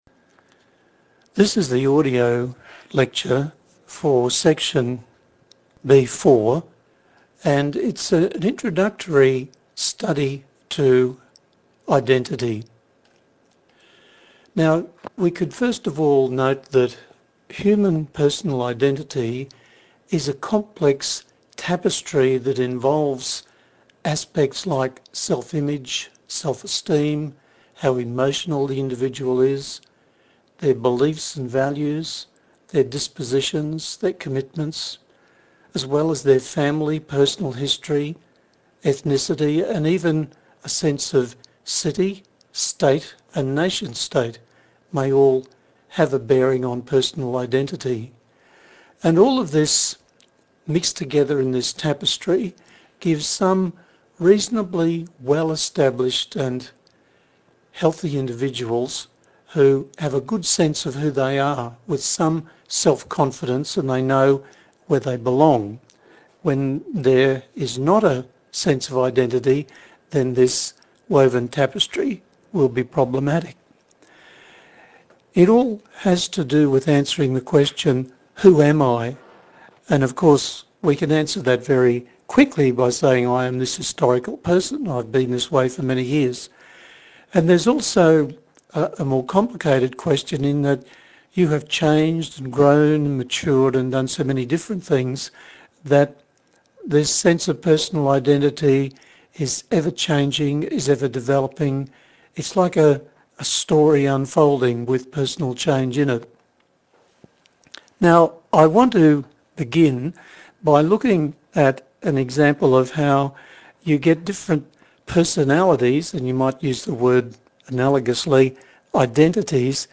The audio file lecture presents the content for this section.